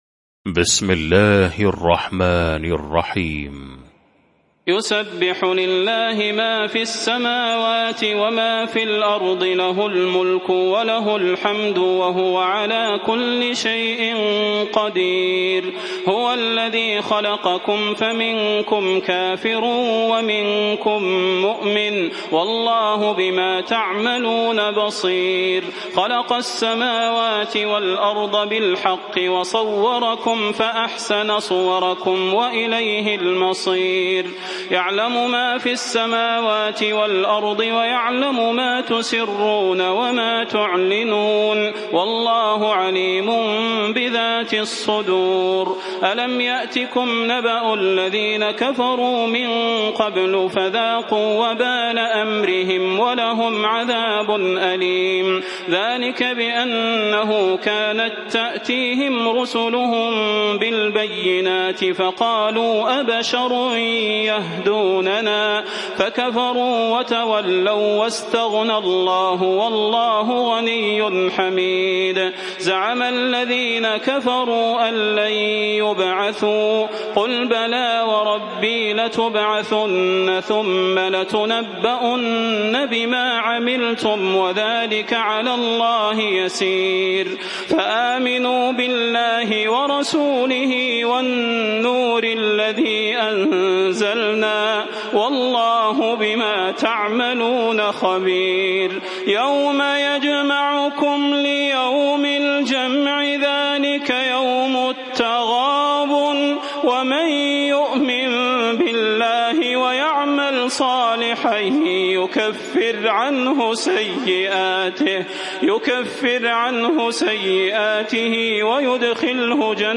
المكان: المسجد النبوي الشيخ: فضيلة الشيخ د. صلاح بن محمد البدير فضيلة الشيخ د. صلاح بن محمد البدير التغابن